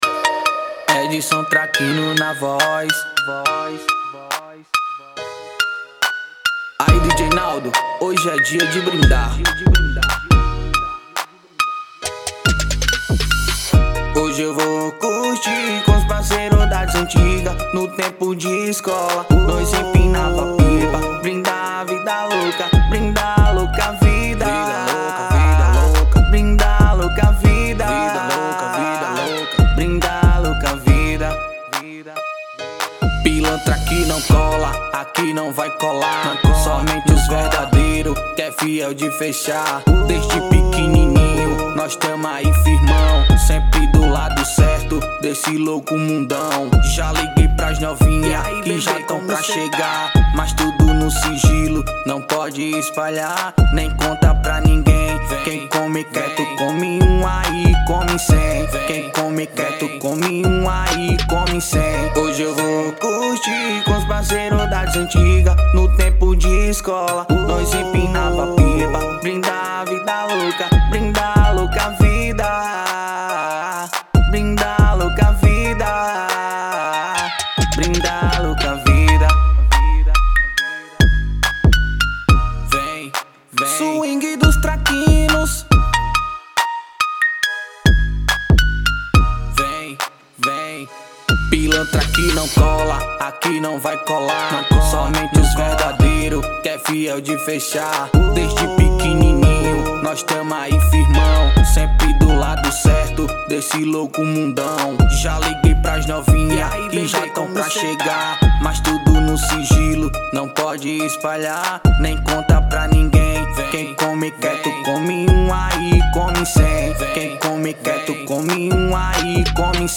EstiloBrega Funk